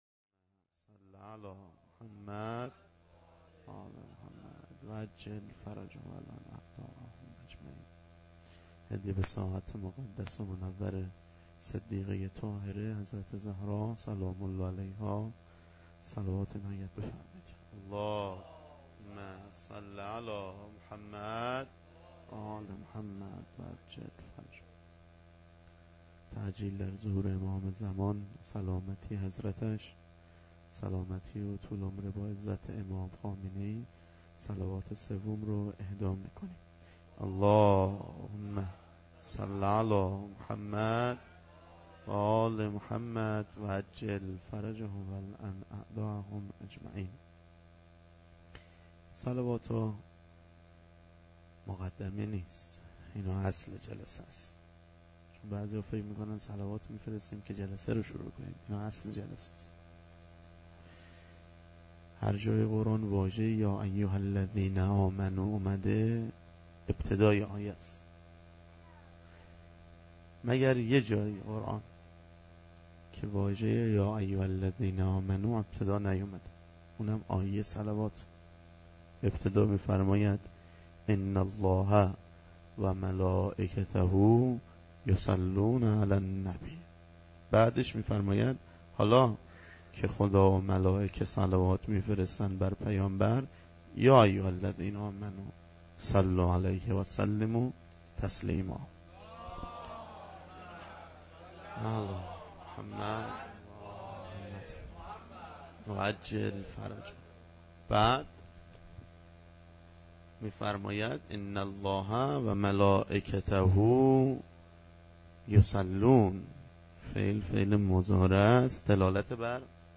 سخنرانی
هیات انصارالمهدی بندرامام خمینی